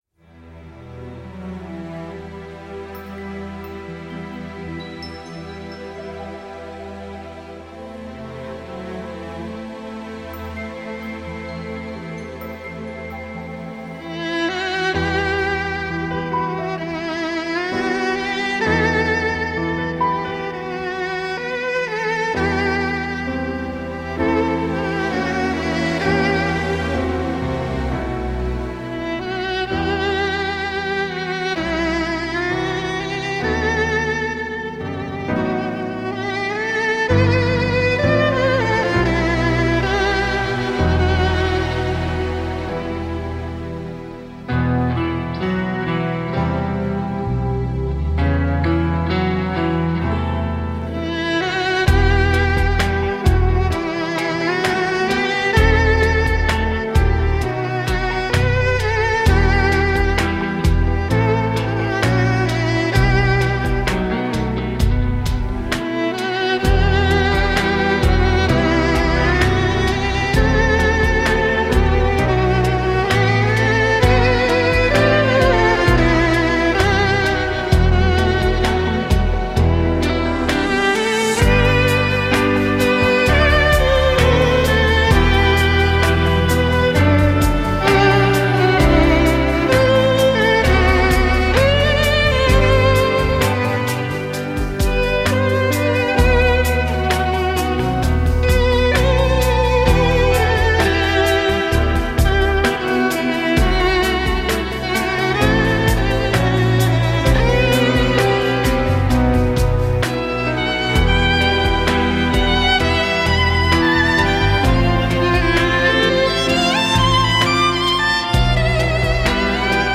Sonata No. 12 in E minor by Paganini, performed by David Garrett (courtesy of davidgarrettmusic):